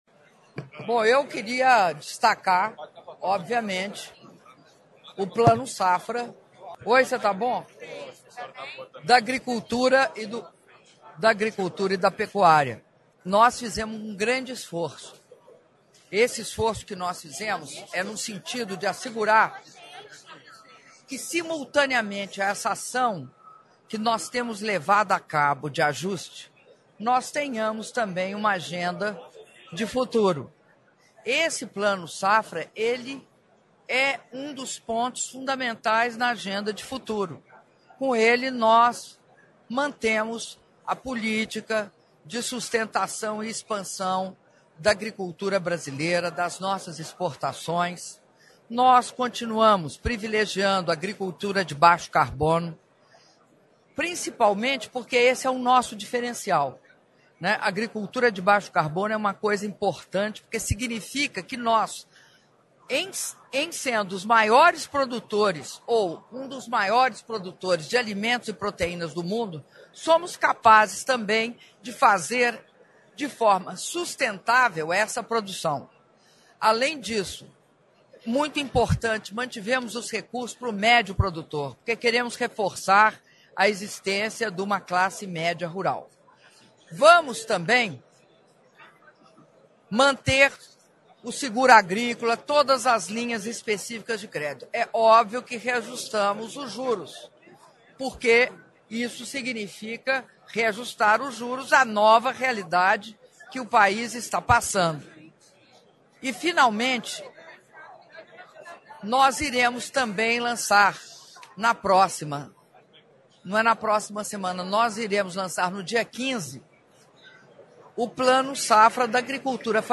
Áudio da entrevista coletiva concedida pela presidenta da República, Dilma Rousseff, após cerimônia de Lançamento do Plano Agrícola e Pecuário 2015/2016 - Brasília/DF (03min46s)